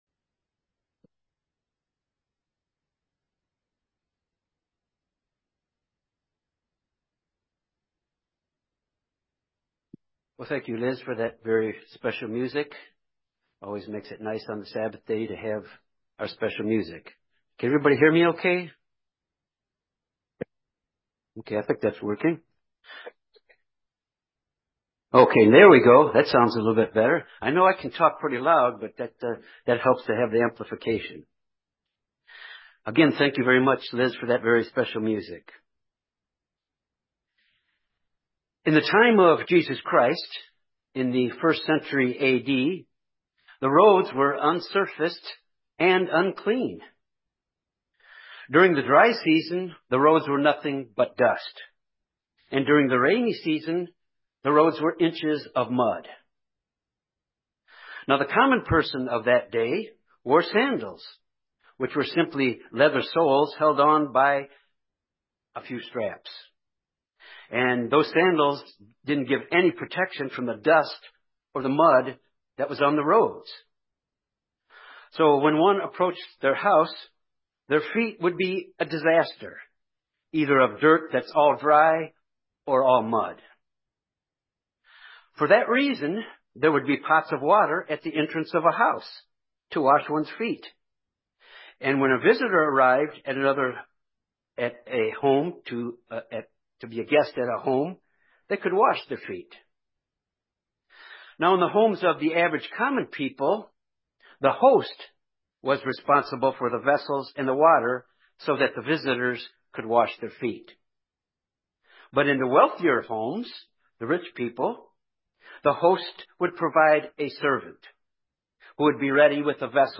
This sermon examines the Importance of the footwashing attitude; an attitude of service to others.